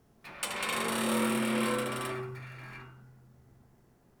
sfx / ambient
Creaking metal
metal_creak5.wav